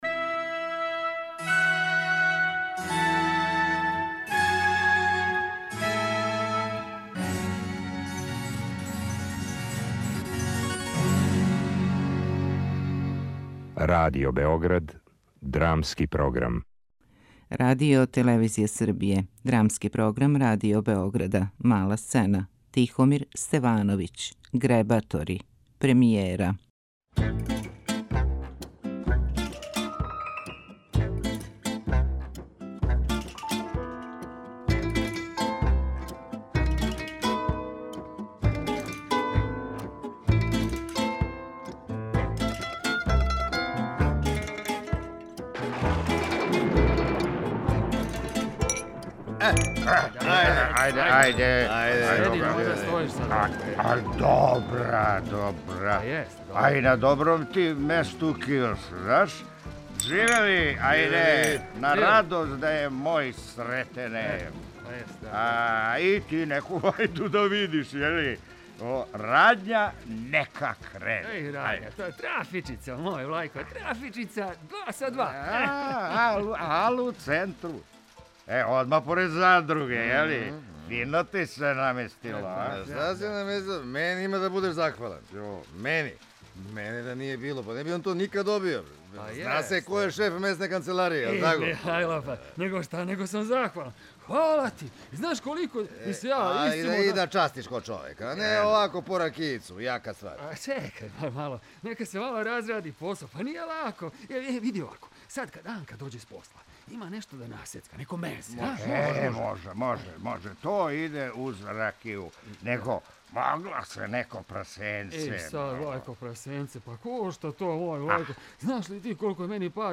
Драмски програм: Тихомир Стевановић: Гребатори (премијера)
Мала сцена